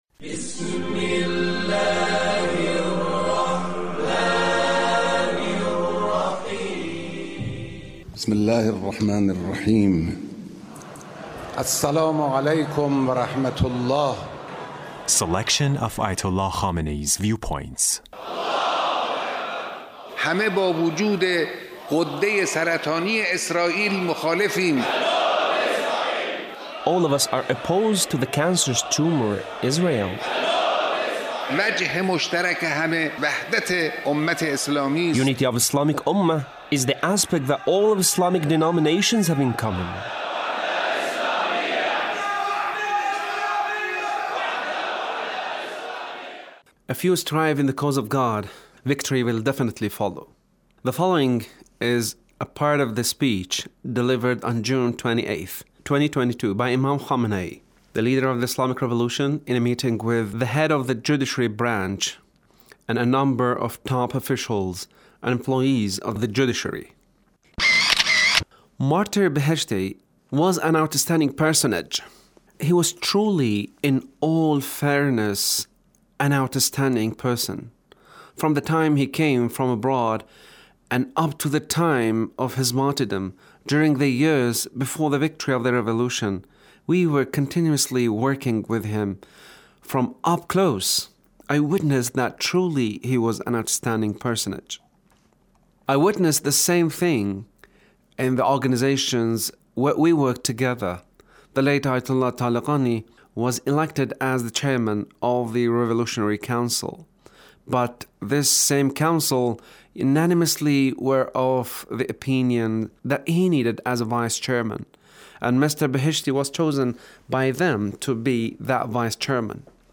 Leader's Speech (1593)
Leader's Speech with Judiciary Officials